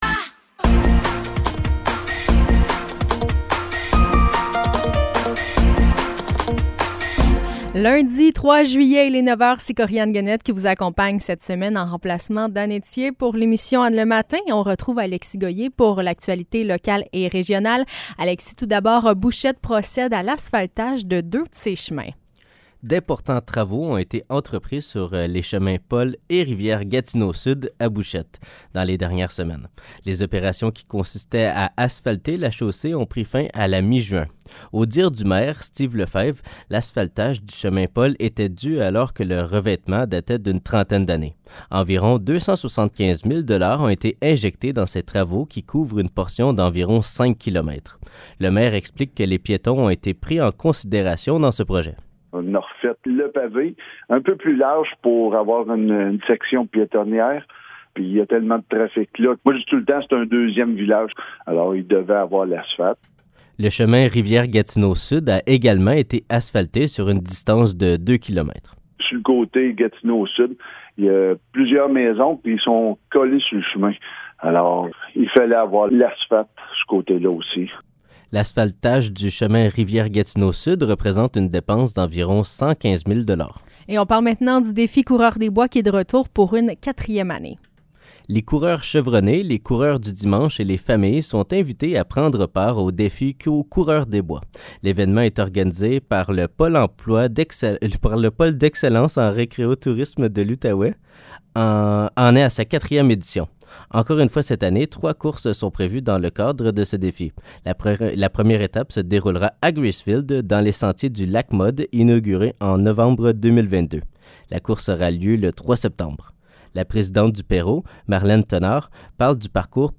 Nouvelles locales - 3 juillet 2023 - 9 h